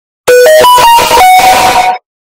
Samsung Notification Earrape Sound Effect Free Download
Samsung Notification Earrape